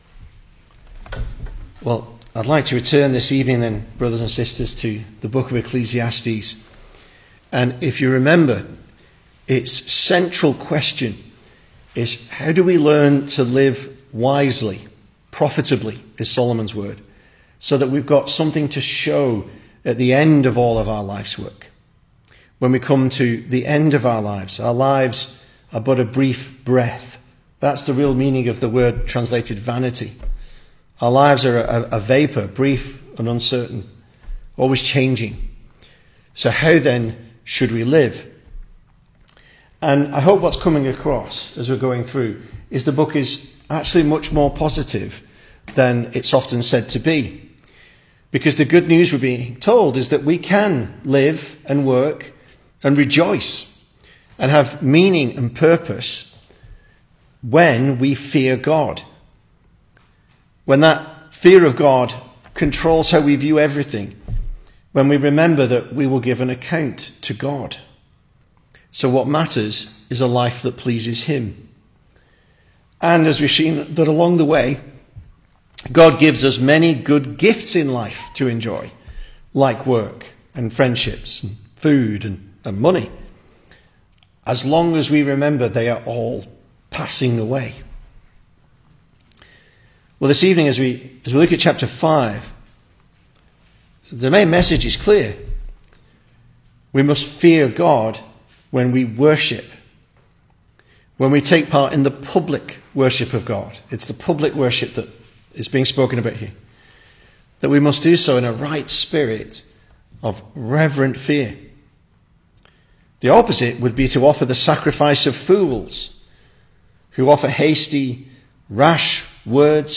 2026 Service Type: Weekday Evening Speaker